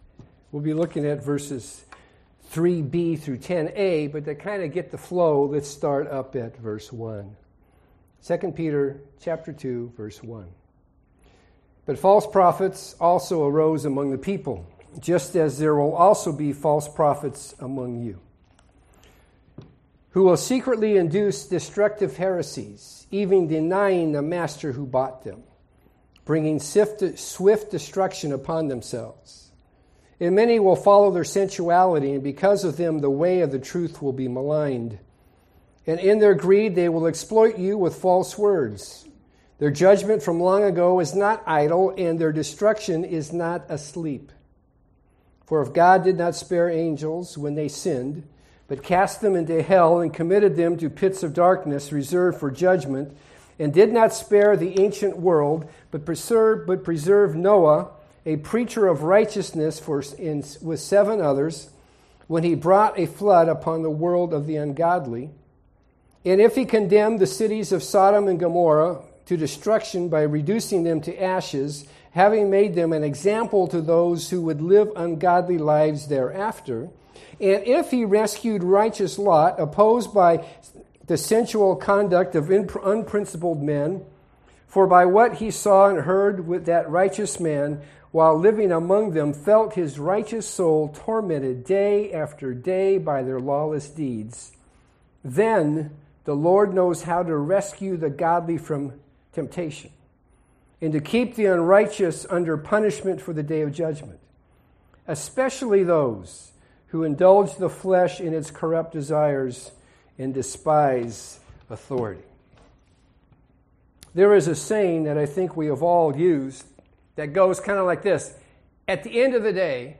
Listen to Sermon from Worship Service: